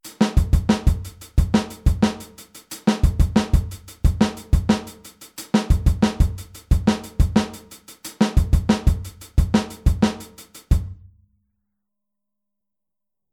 Groove Nr. 13 → Aufteilung linke und rechte Hand auf HiHat und Snare - Musikschule »allégro«
Aufteilung linke und rechte Hand auf HiHat und Snare
Groove13-16tel.mp3